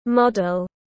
Người mẫu tiếng anh gọi là model, phiên âm tiếng anh đọc là /ˈmɑːdl/.
Model /ˈmɑːdl/